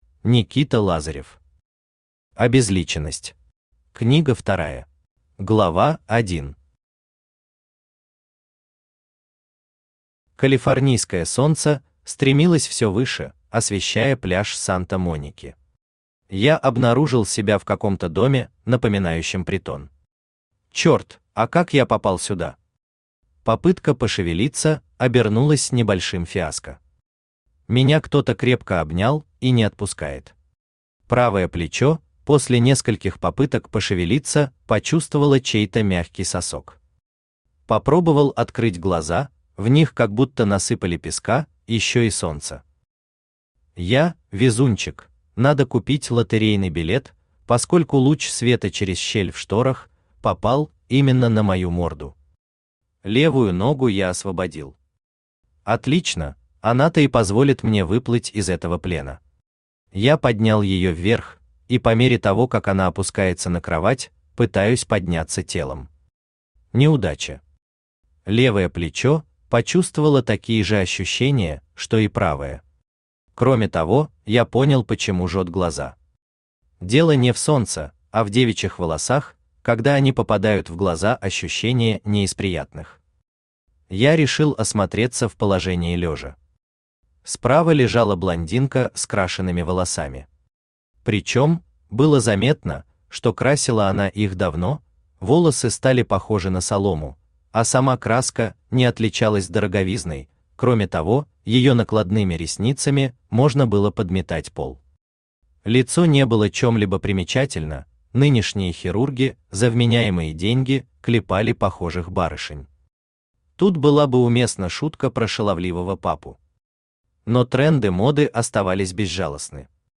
Аудиокнига Обезличенность. Книга вторая | Библиотека аудиокниг
Книга вторая Автор Никита Владимирович Лазарев Читает аудиокнигу Авточтец ЛитРес.